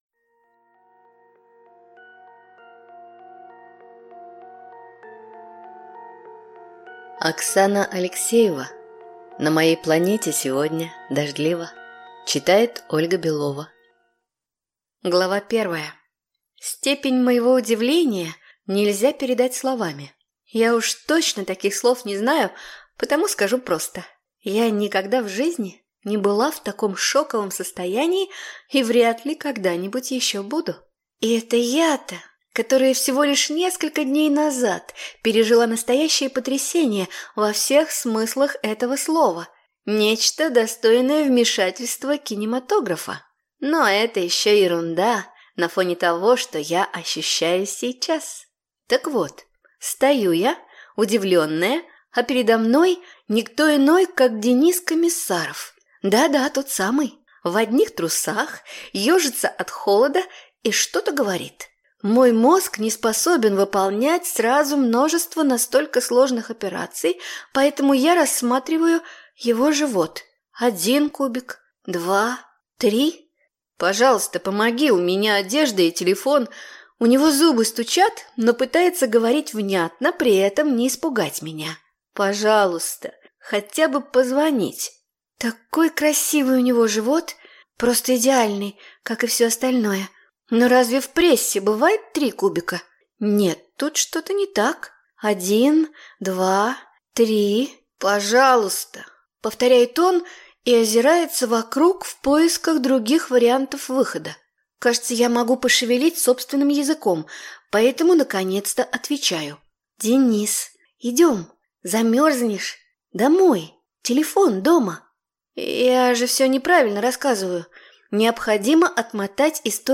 Аудиокнига На моей планете сегодня дождливо | Библиотека аудиокниг